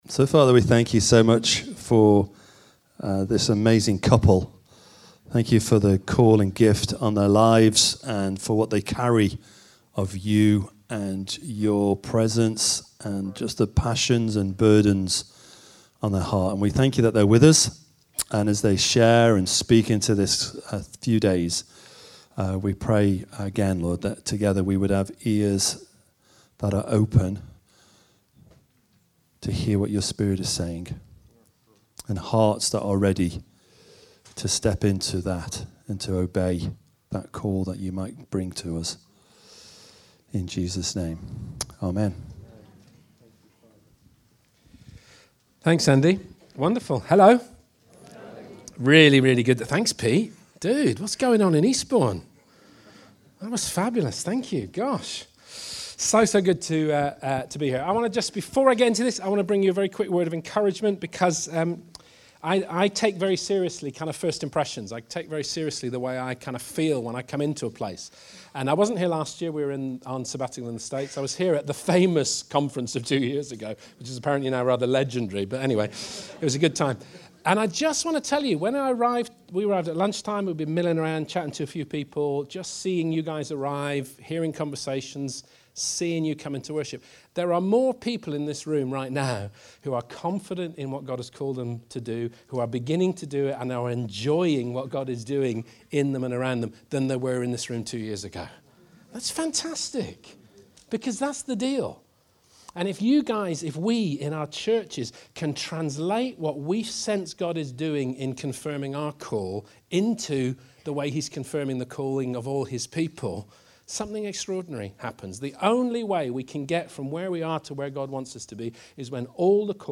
Session 1 Strangers and Angels (Conference 2019)